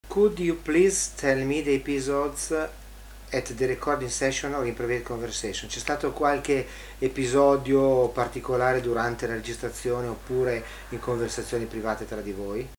Una intervista